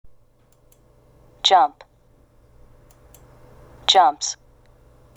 ２】sをつけて「ｓ(ス)」と発音する動詞 (kやpの音で終わる単語)